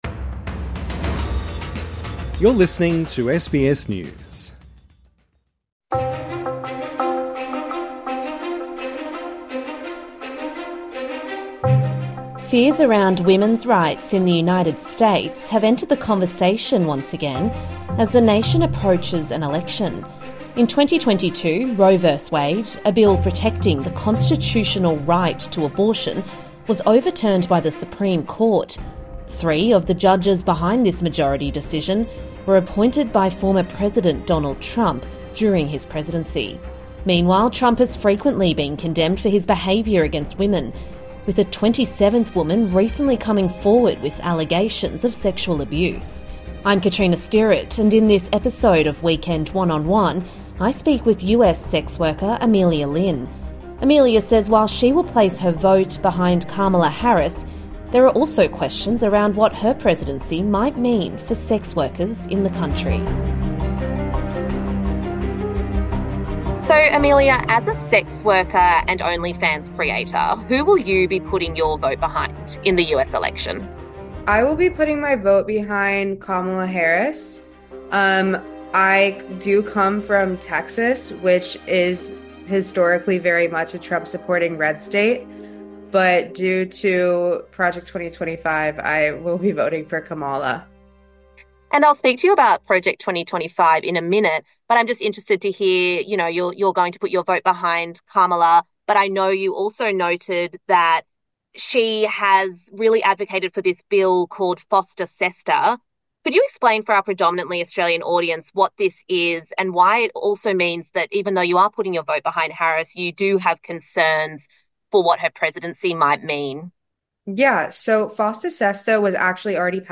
INTERVIEW: SBS speaks to a US sex worker who shares her fears around women's rights ahead of the election